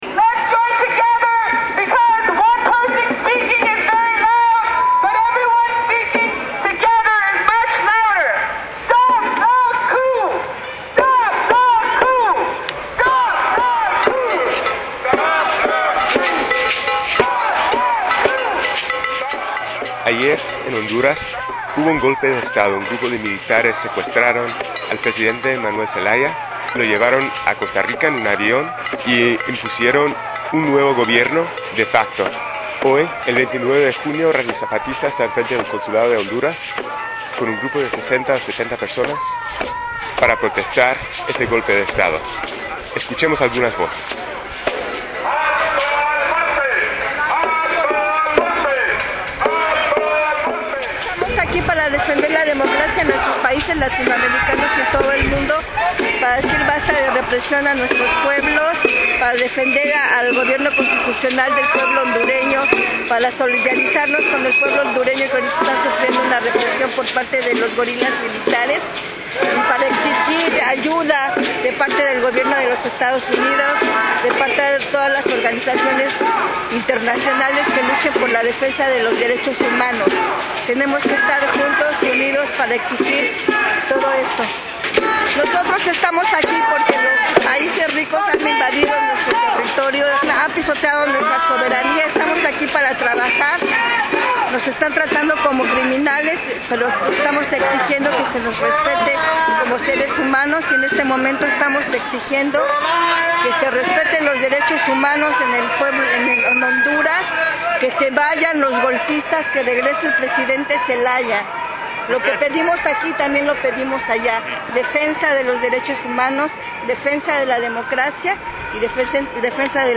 honduras_protest.mp3